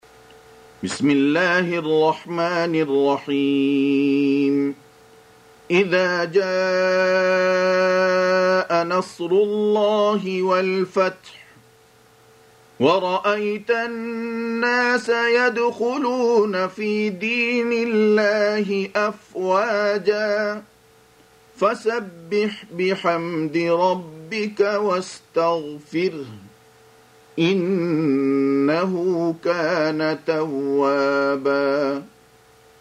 Surah Repeating تكرار السورة Download Surah حمّل السورة Reciting Murattalah Audio for 110. Surah An-Nasr سورة النصر N.B *Surah Includes Al-Basmalah Reciters Sequents تتابع التلاوات Reciters Repeats تكرار التلاوات